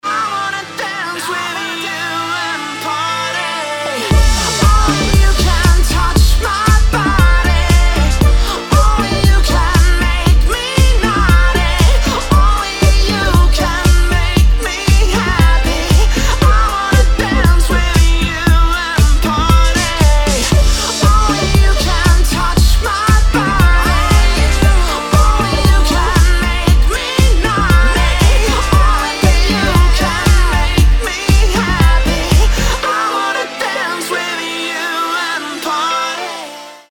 мужской вокал
deep house
dance